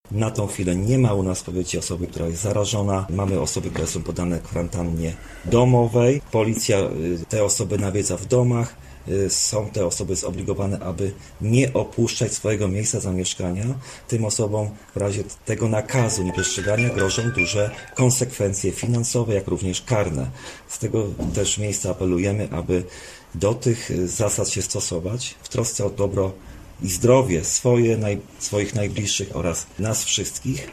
Mówi starosta Marcin Piwnik: